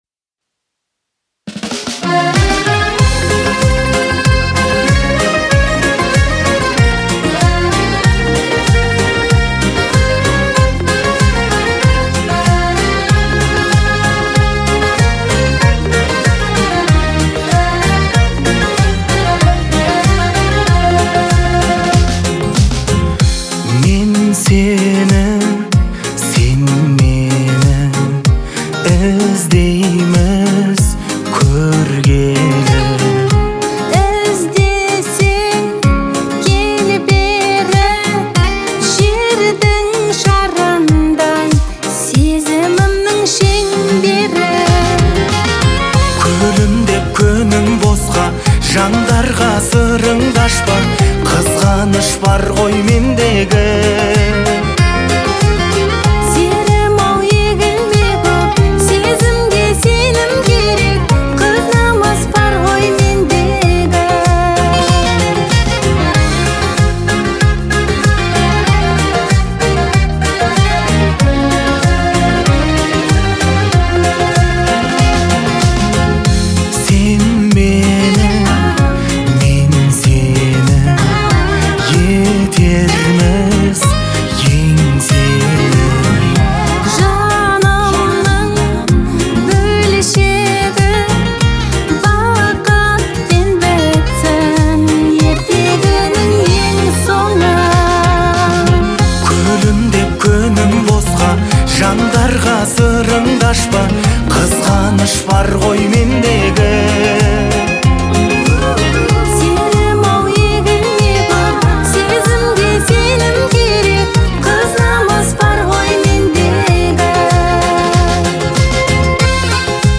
это яркая и мелодичная песня в жанре поп